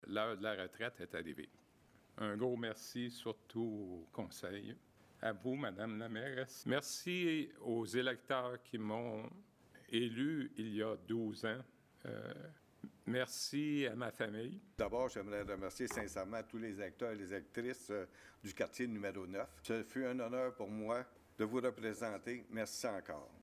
La Ville de Granby a rendu hommage à deux conseillers municipaux qui ont pris leur retraite de la politique municipale mercredi soir à l’occasion de la séance du conseil.
Tant Robert Riel, qui a été aussi policier à Granby pendant plus de 30 ans que Robert Vincent, qui a représenté la population sur la scène fédérale avec le Bloc Québécois pendant huit ans et 12 ans au niveau municipal, ont tenu à s’adresser à leurs collègues conseillers ainsi qu’à la population de Granby :